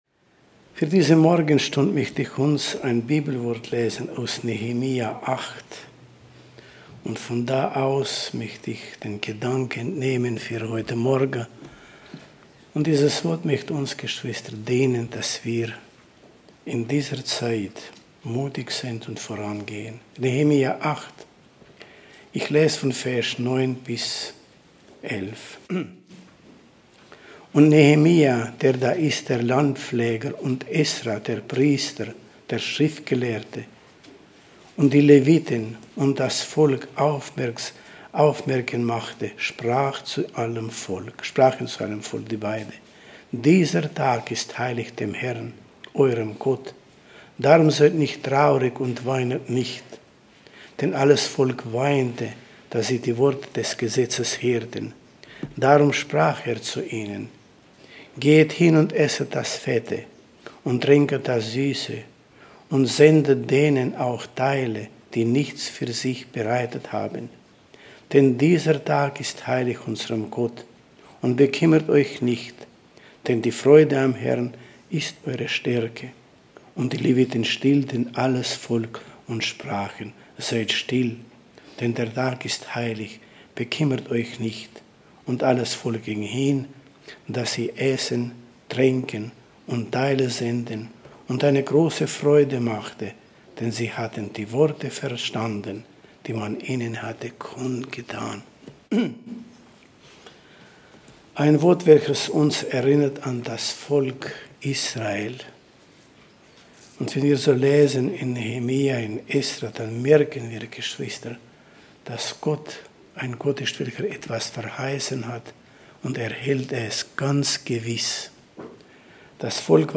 Predigten 2021 - Gemeinde Gottes Weil am Rhein